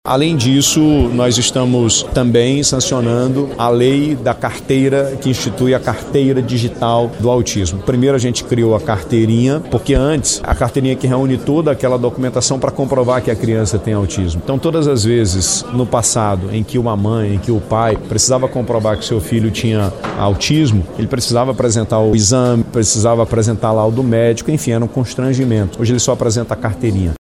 O evento foi realizado no Centro de Convenções Vasco Vasques, em Manaus e comemorou o Dia Nacional da Acessibilidade e o Dia Internacional da Pessoa com Deficiência.
SONORA-2-GOVERNADOR-WILSON-LIMA-.mp3